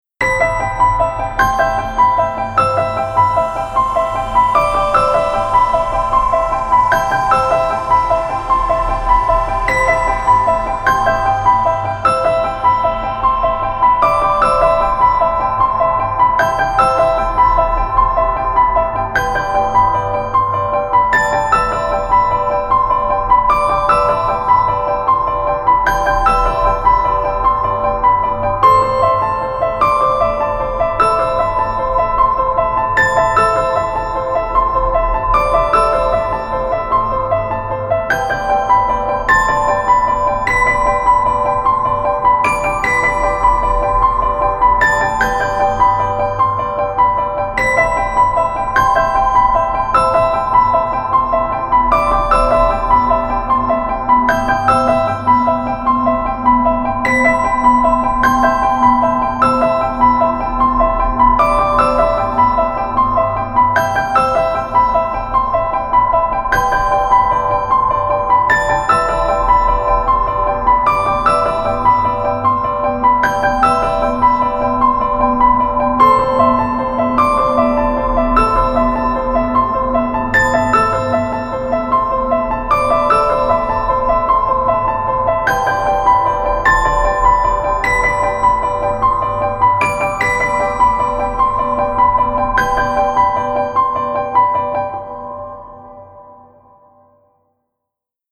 原曲
テンポ：♪=76
主な使用楽器：ピアノ、シンセパッド、シンセベル etc